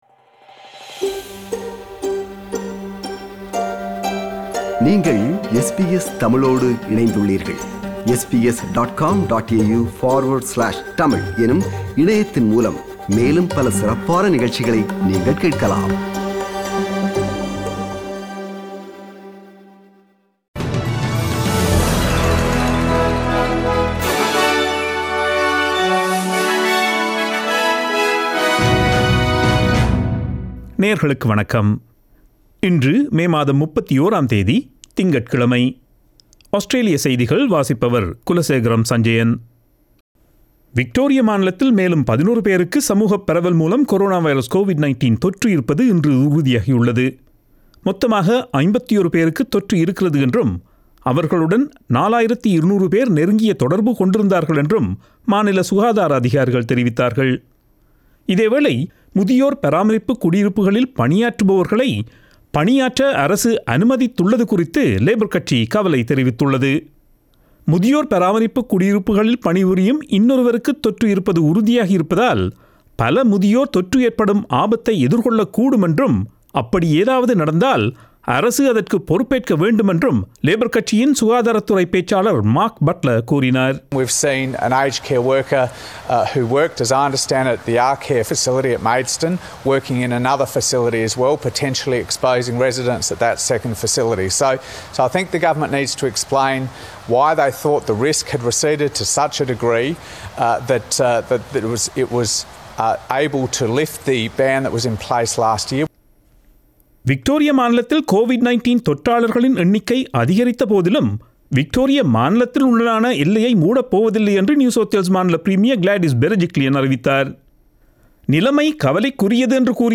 Australian news bulletin for Monday 31 May 2021.